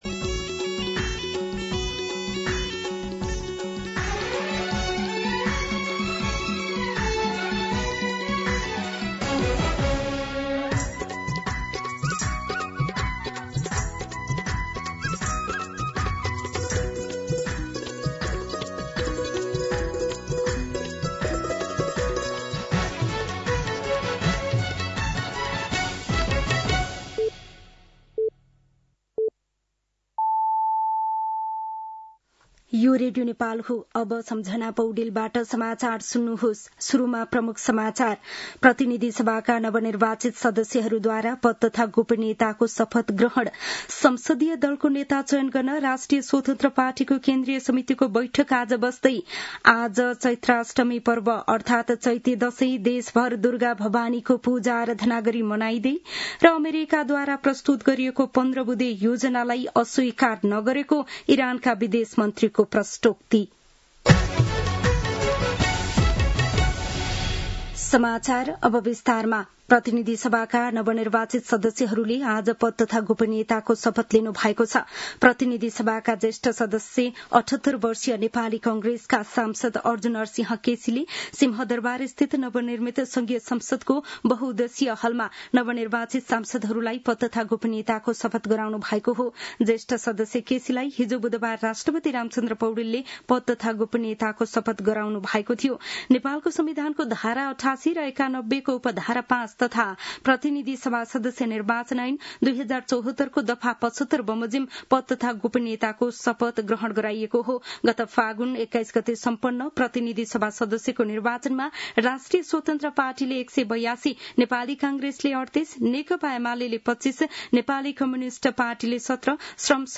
दिउँसो ३ बजेको नेपाली समाचार : १२ चैत , २०८२